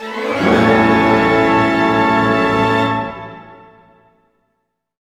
Index of /90_sSampleCDs/Roland L-CD702/VOL-1/ORC_Orch Gliss/ORC_Major Gliss